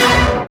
SYN DANCE0DR.wav